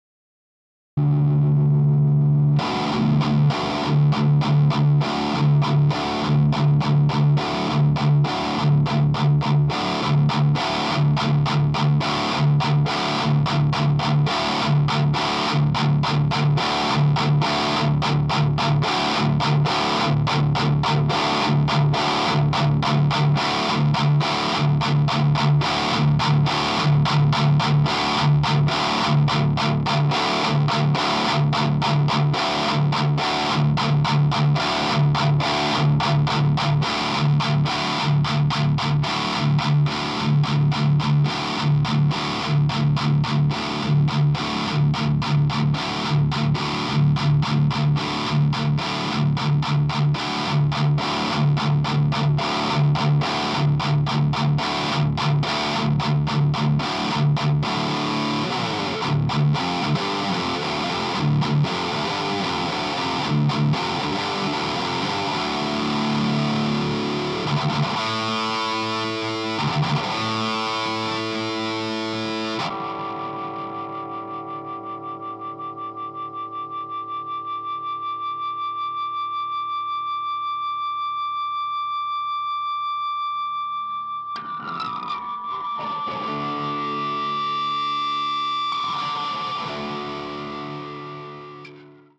вот тут начало - все на 12 часов (OD - канал), на 10 секунде - прибавил Treble, на 38 провалил - MIDDlE, бас убавлял.. было ли где -нибудь по времени норм звучание ?